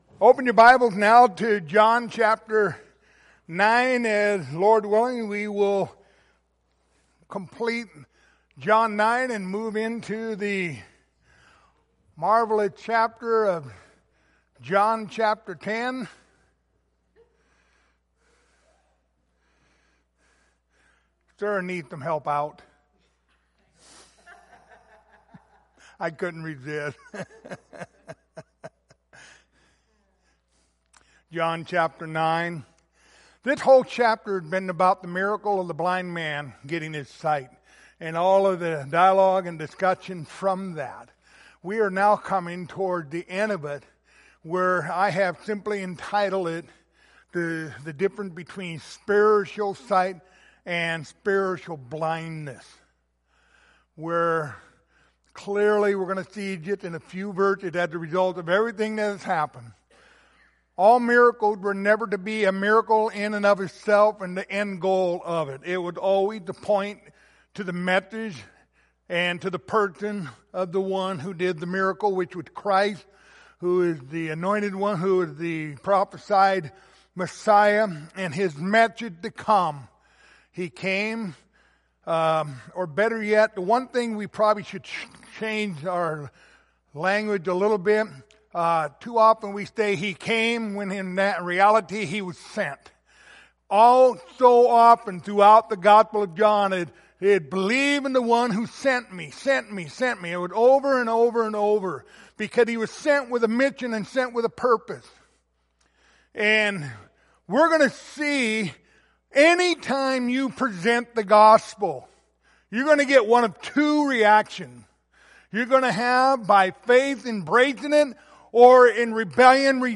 Passage: John 9:35-41 Service Type: Wednesday Evening